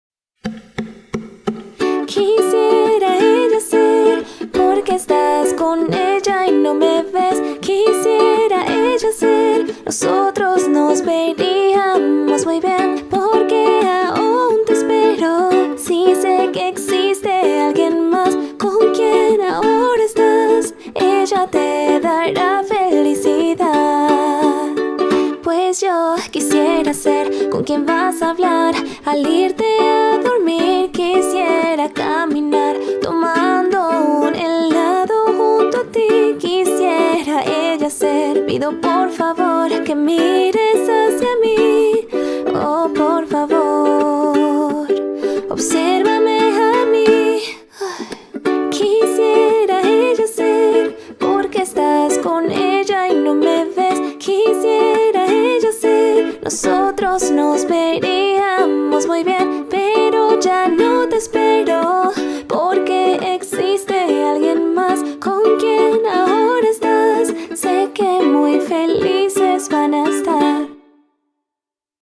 BPM175
Audio QualityPerfect (Low Quality)